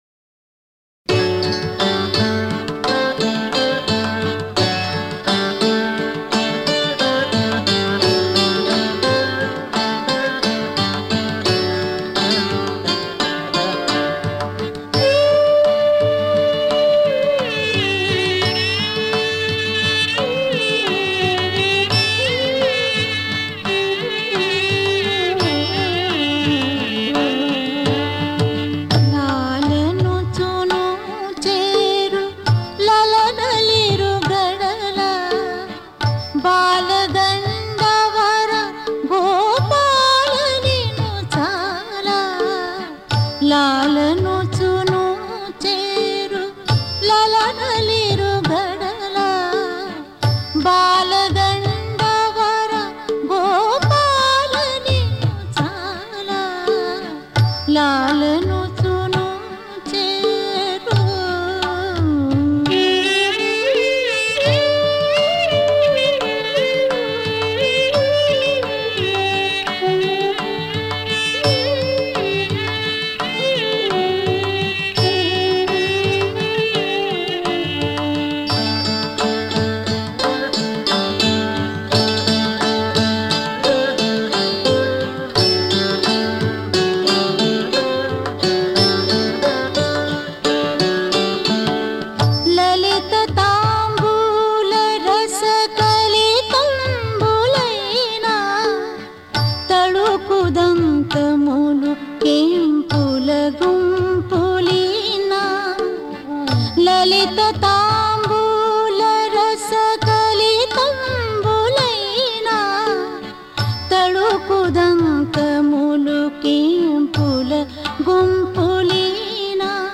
లాలనుచు నూచేరు లలనలిరుగడల | బాలగండవీర గోపాలబాల || -- (నీలాంబరి)
ఉదుట గుబ్బల సరసము లుయ్యాల లూగ పదరి కంకణరవము బహుగతులమ్రోగ వొదిగి చెంపల కొప్పు లొక్కింత వీగ ముదురు చెమటల నళికములు తొప్పదోగ || -- (మోహన)
సొలపు తెలిగన్నుగవ చూపులిరువంక మలయు రవళులకు బహుమారును బెళంక కొలది కోవిగములు క్రోలుమదనాంక- ములగ్రేణిసేయు రవములు వడిదలంక || -- (హుస్సేని)